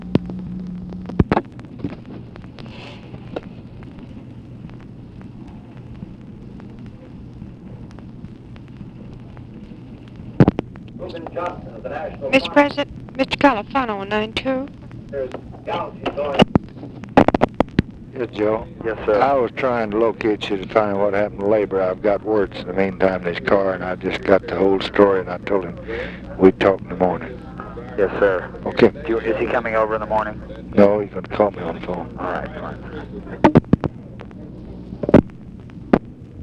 Conversation with JOSEPH CALIFANO, August 9, 1966
Secret White House Tapes